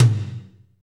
Index of /90_sSampleCDs/Northstar - Drumscapes Roland/TOM_Toms 1/TOM_S_S Toms x
TOM S S M05R.wav